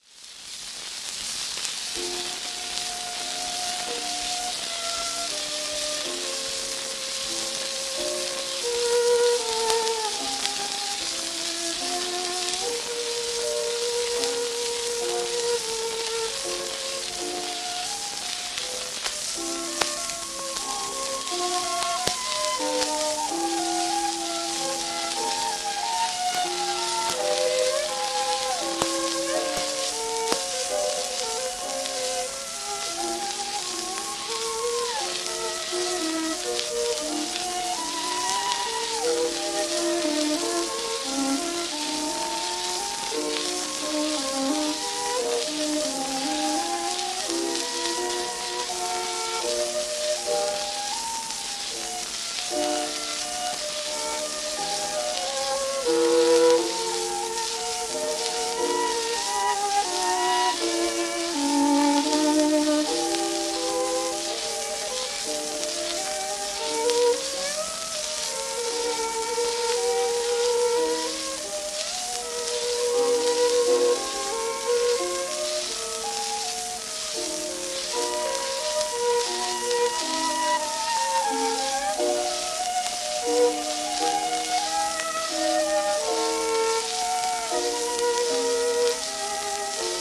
80rpm